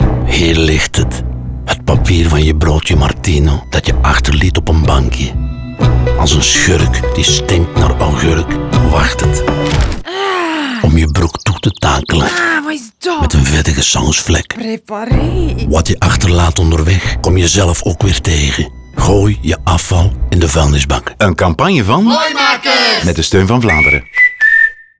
Radiospot Broodjespapier